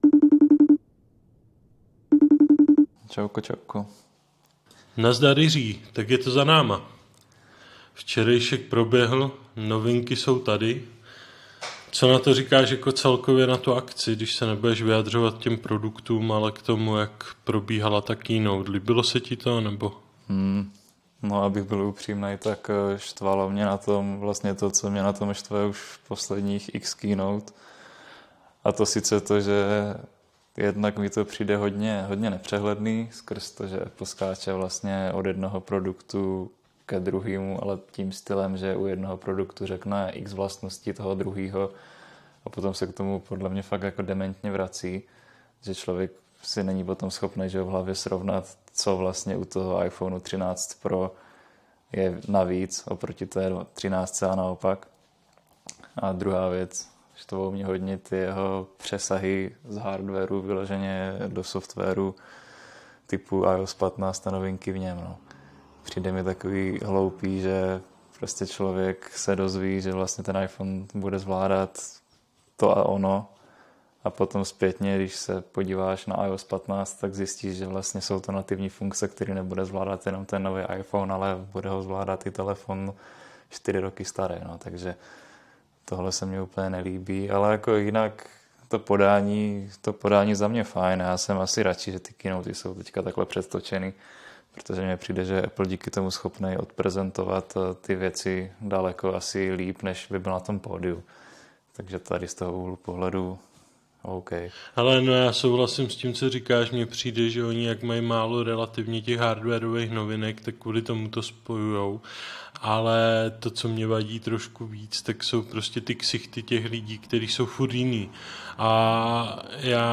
Páteční pokec přes FaceTime vol. 16